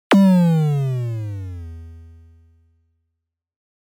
알림음(효과음) + 벨소리
알림음 8_Blast5.mp3